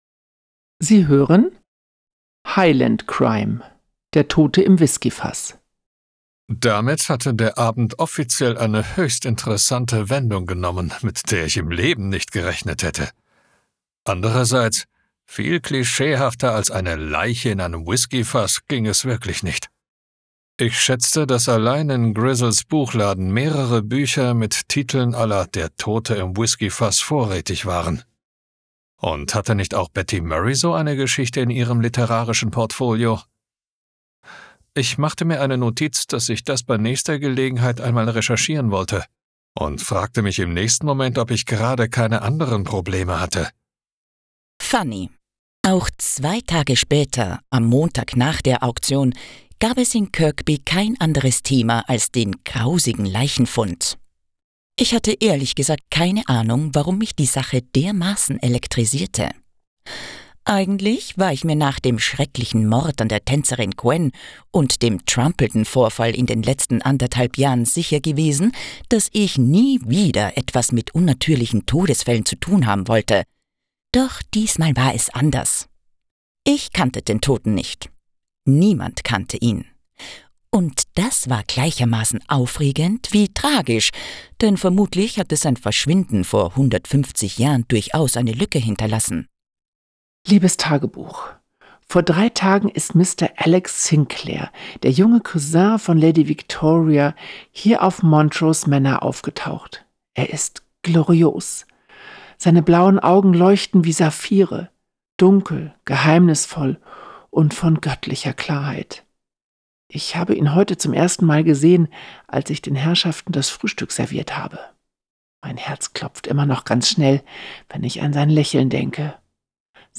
Highland-Crime-–-Der-Tote-im-Whiskyfass-Hoerprobe.wav